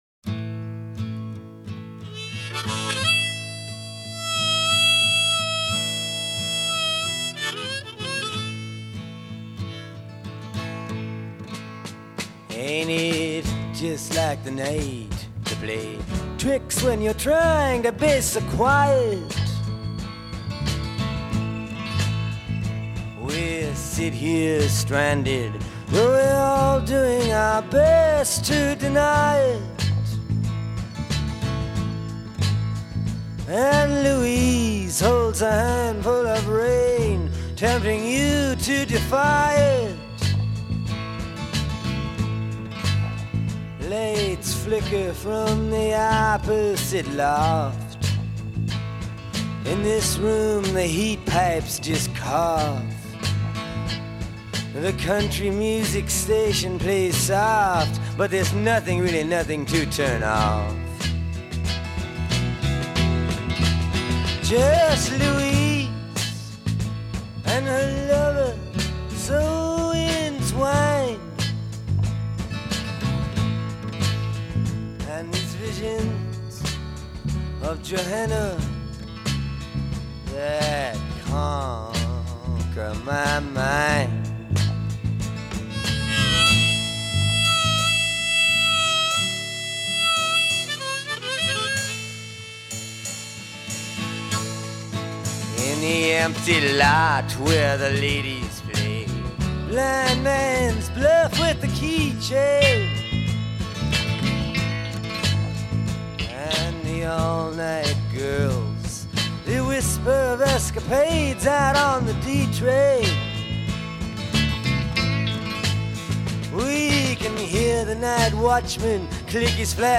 And it sounds like it — confident, brazen and brand-new.